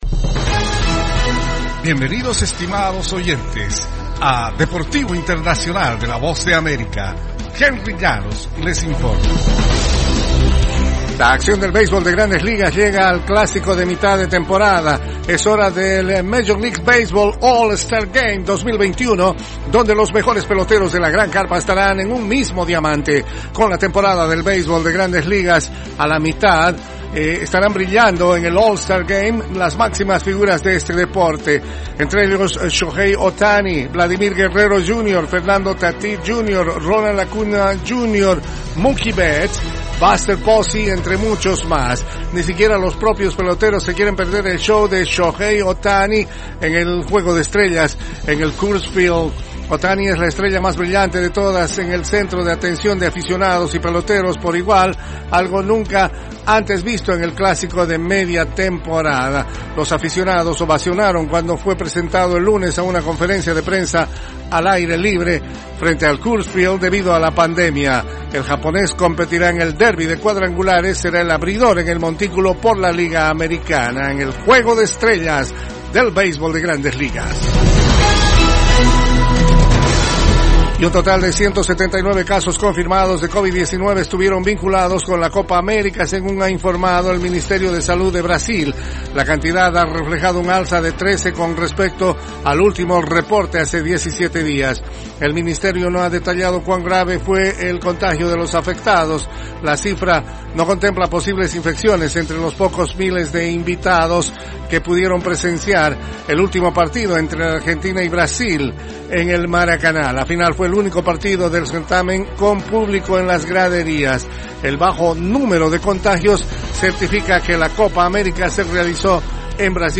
Las noticias deportivas llegan desde los estudios de la Voz de América en la voz de